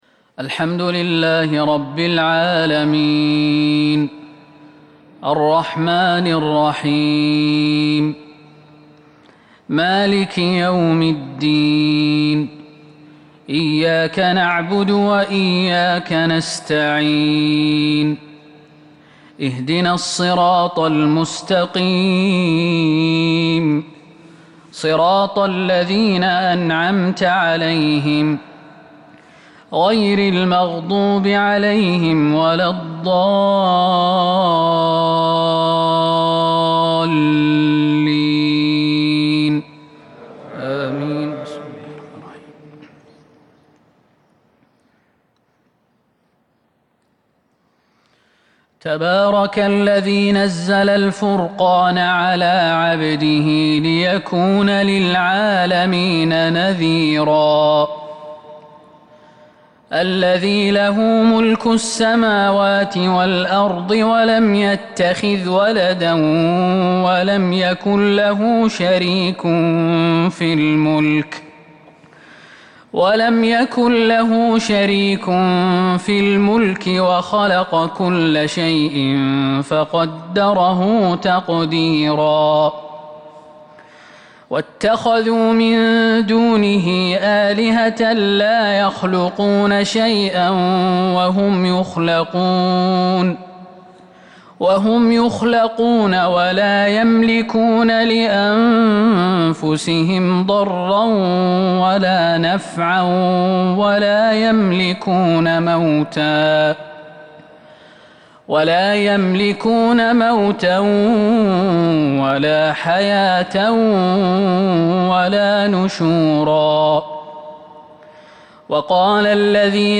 صلاة الفجر الاثنين 5/1/1442 من سورة الفرقان salat alfajr 24/8/2020 surat alfurqan > 1442 🕌 > الفروض - تلاوات الحرمين